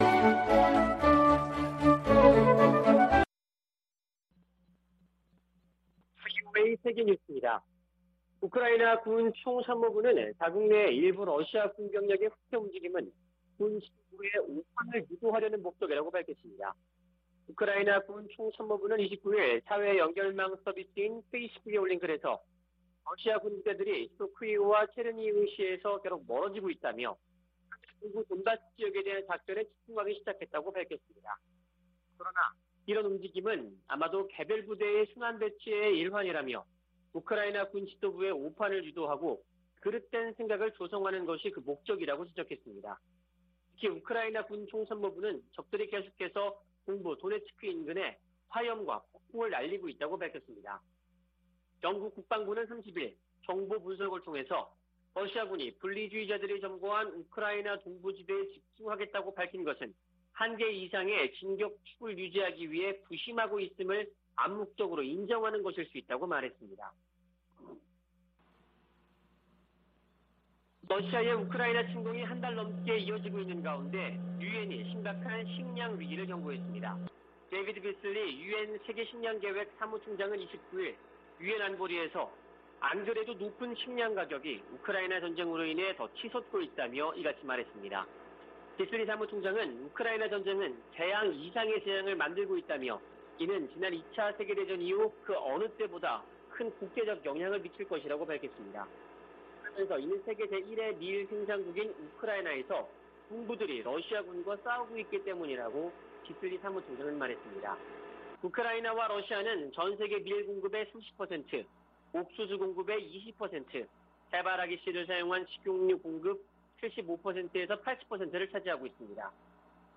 VOA 한국어 아침 뉴스 프로그램 '워싱턴 뉴스 광장' 2022년 3월 31일 방송입니다. 조 바이든 미국 대통령과 리셴룽 싱가포르 총리가 북한의 잇따른 탄도미사일 발사를 규탄하고 대화로 복귀할 것을 촉구했습니다.